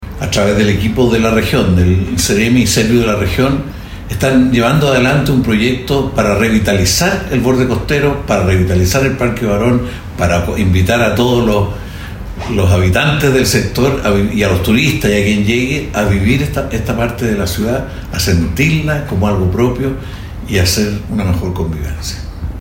En este contexto, el ministro de Vivienda, Carlos Montes, dijo que la iniciativa busca recuperar el borde costero, tanto para visitantes, como para habitantes de la ciudad puerto.